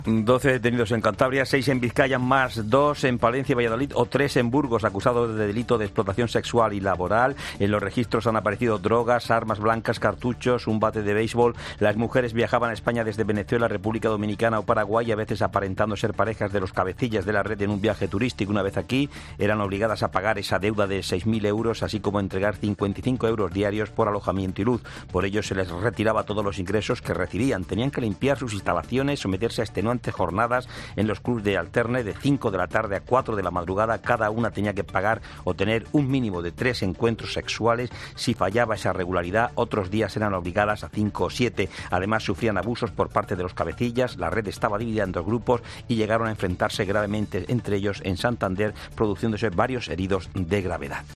Crónica